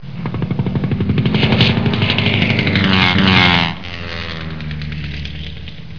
دانلود آهنگ طیاره 22 از افکت صوتی حمل و نقل
دانلود صدای طیاره 22 از ساعد نیوز با لینک مستقیم و کیفیت بالا
جلوه های صوتی